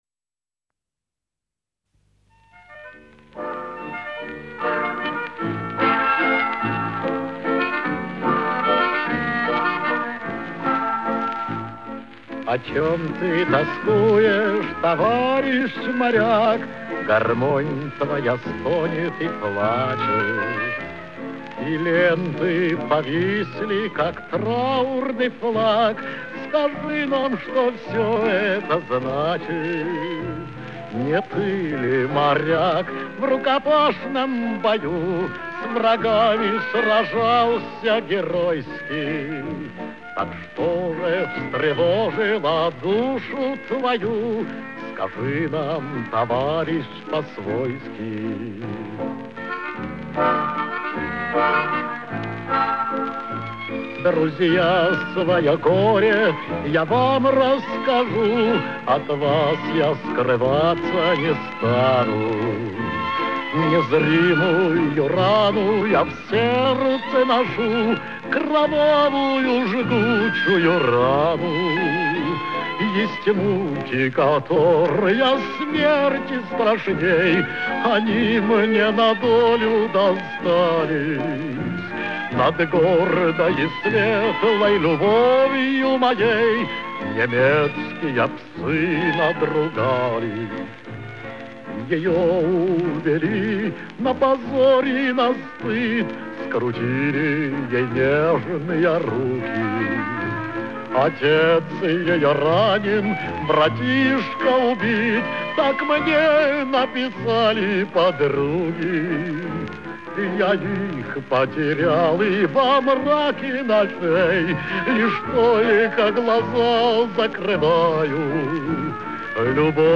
Главная / Computer & mobile / Мелодии / Патриотические песни